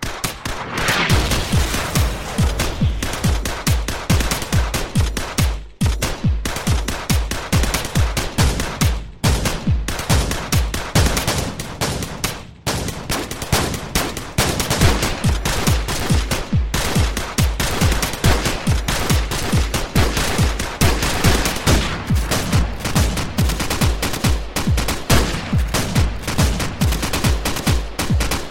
Categoria Eletronicas